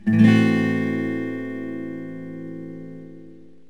Am6.mp3